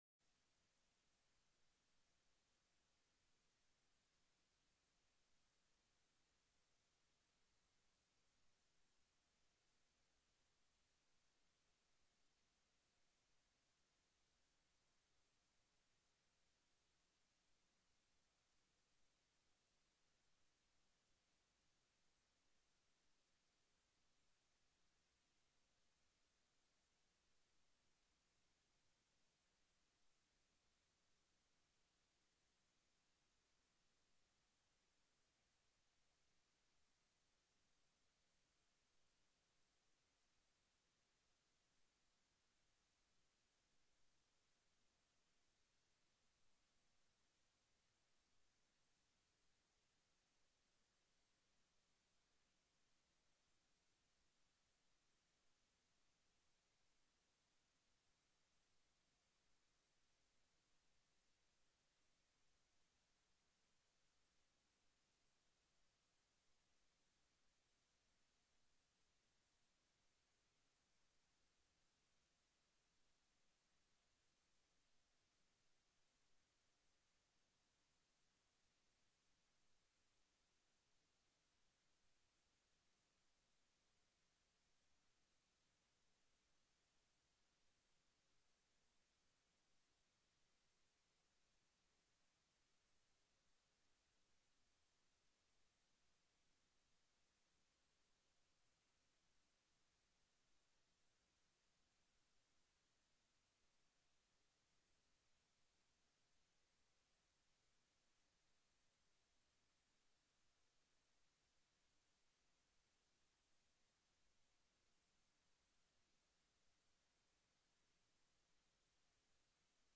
The audio recordings are captured by our records offices as the official record of the meeting and will have more accurate timestamps.
+ teleconferenced
Co-Chair Hoffman called the Conference Committee meeting on